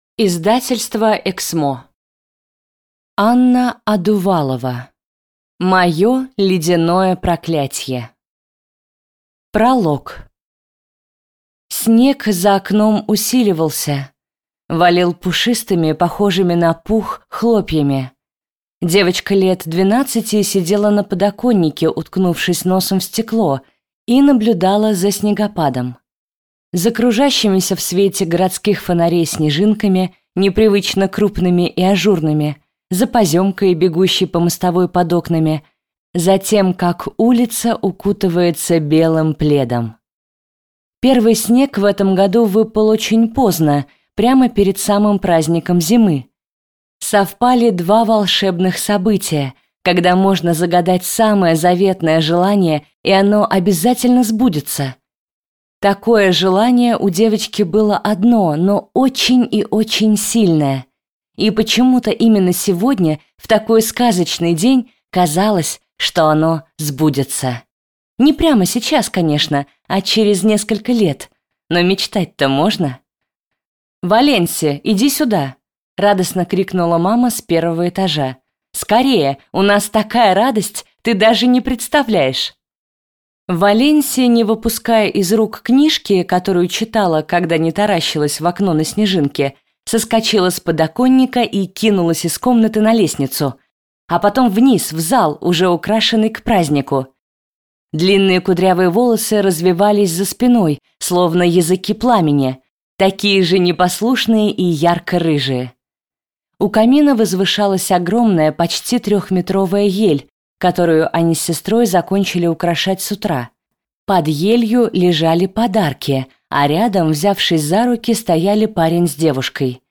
Аудиокнига Мое ледяное проклятье | Библиотека аудиокниг